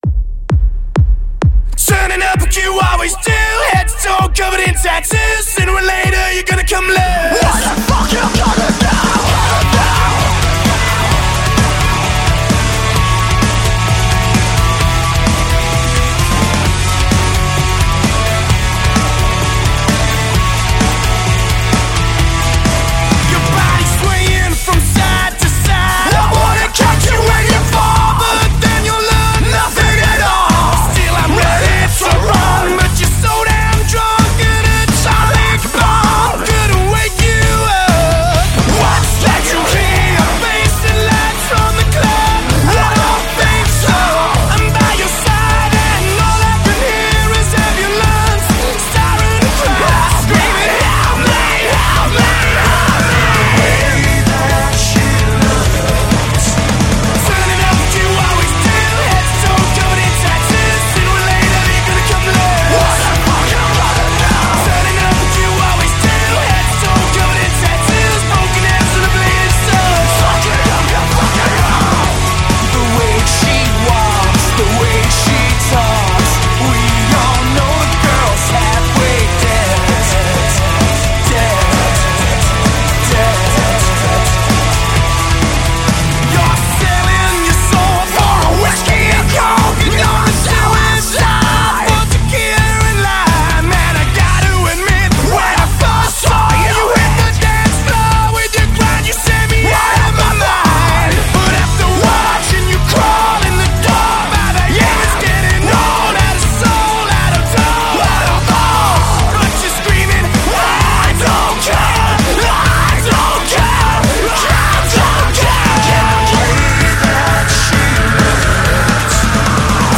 风格：旋律死亡金属, 力量金属, 重金属